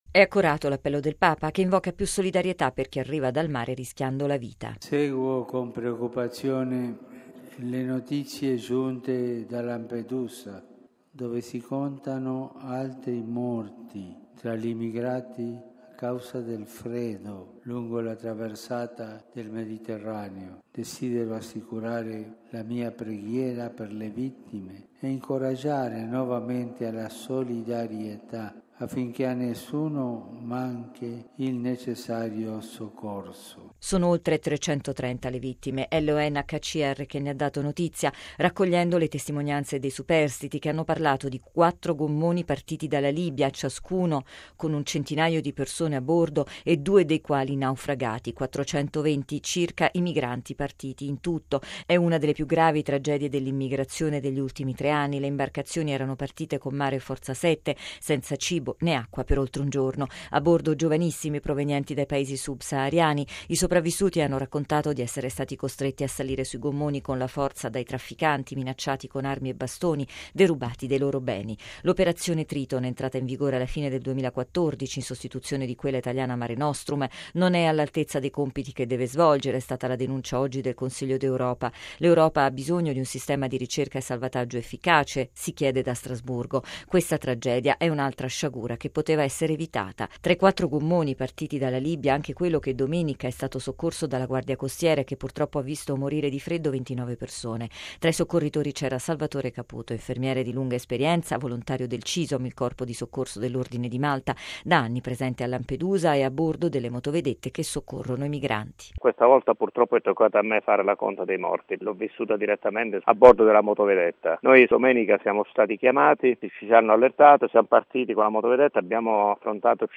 E all’udienza generale alto si è levato il richiamo del Papa.